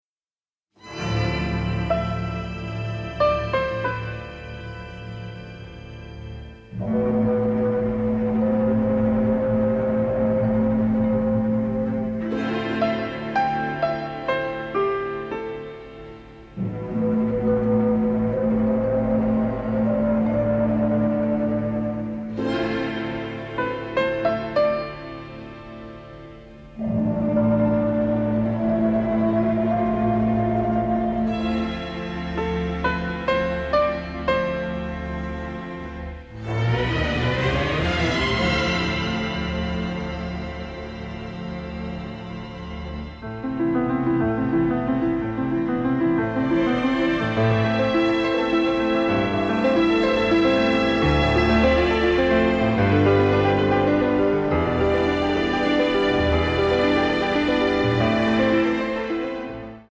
Instrumental
Romantic and melancholic